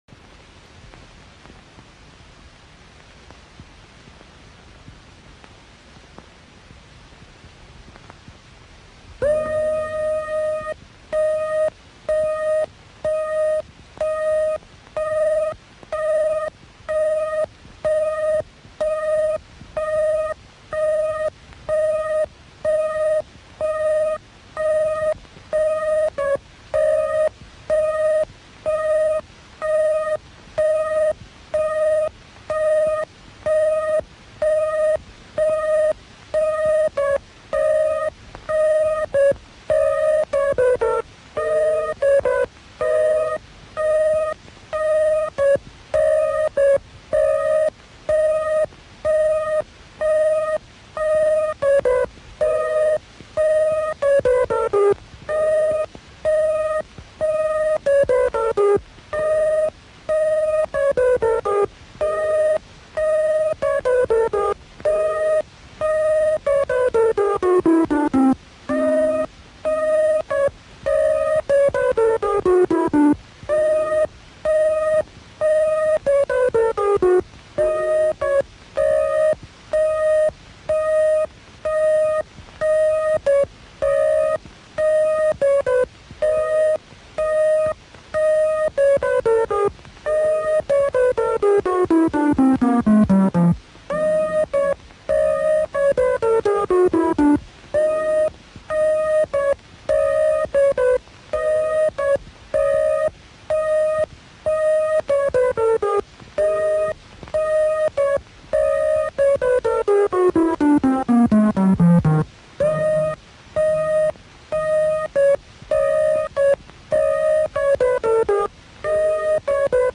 The soundtrack itself was output to a sprocketed audio tape.
The increments along a search were 4 frames per position (0.25secs at 16 frames per sec).